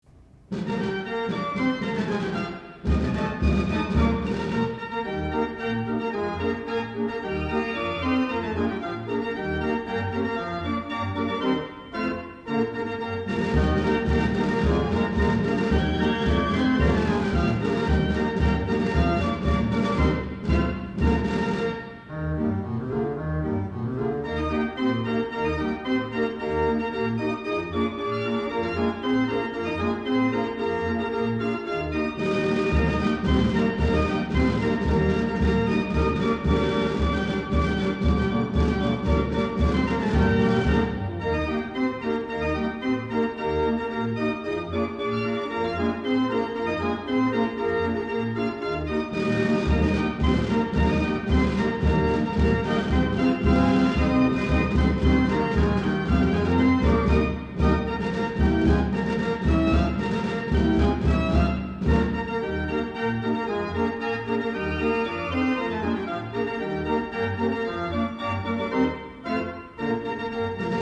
Hooghuys Fair Organ